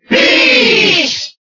Category:Crowd cheers (SSBB) You cannot overwrite this file.
Peach_Cheer_Italian_SSBB.ogg